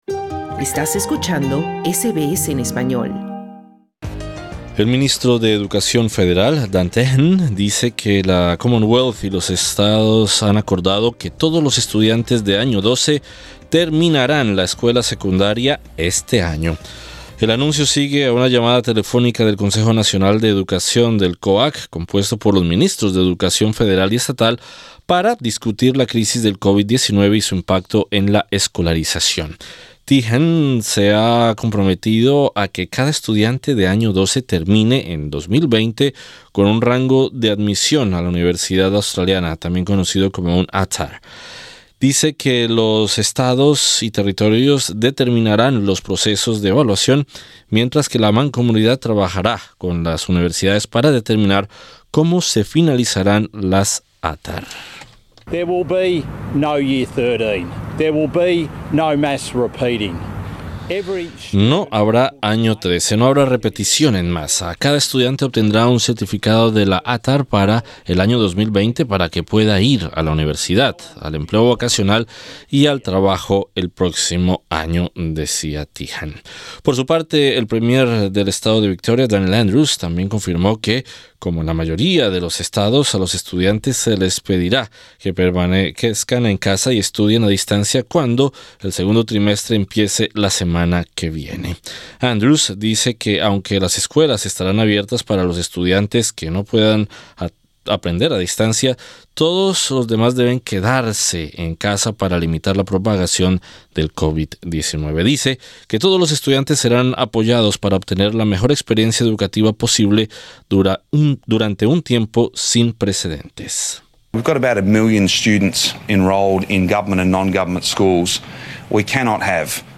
Sobre como se vislumbra este escenario, conversamos con el profesor de primaria de una escuela pública en el estado de Victoria